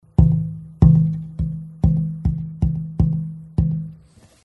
These are audio clips from the 2011 convention workshop.
B-Grade Adirondack. The tone is a little bit muddy, but the footprint of a smaller guitar will tighten it up. Variable grain spacing and a small knot make this a $7 top.